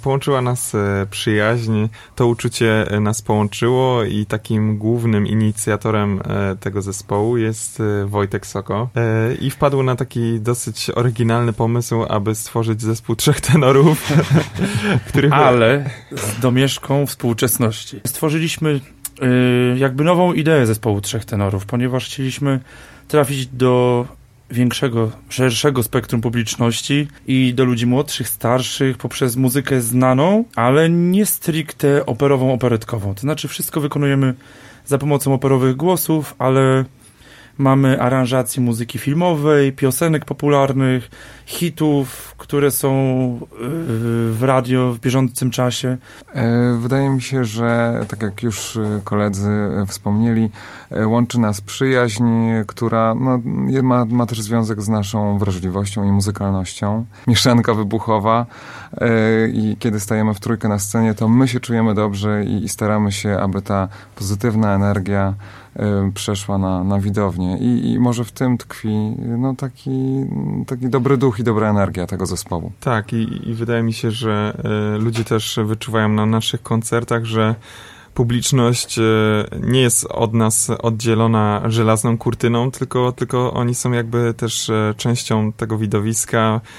Trzy indywidualności sceniczne i trzy doskonale zgrane głosy.
Tenorzy swobodnie łączą różne gatunki muzyki.